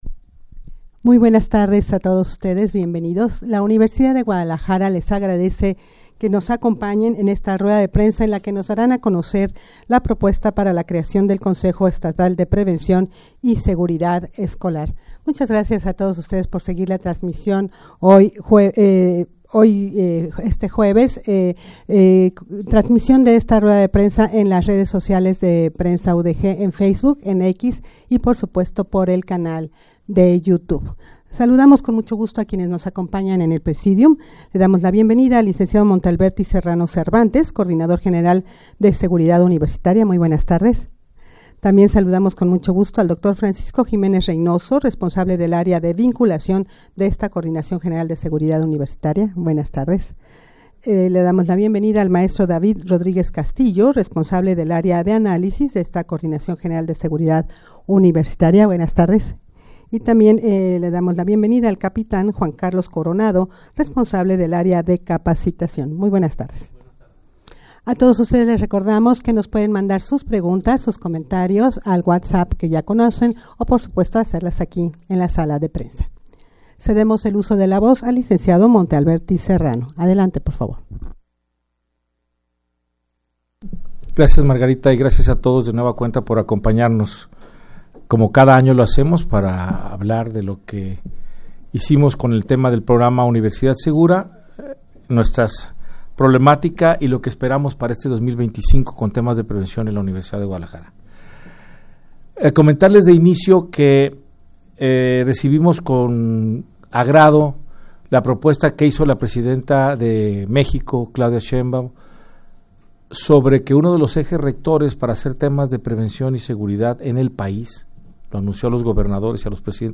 rueda-de-prensa-para-dar-a-conocer-propuesta-para-la-creacion-del-consejo-estatal-de-prevencion-y-seguridad-escolar.mp3